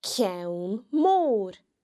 The broad nn can be heard in ceann (a head):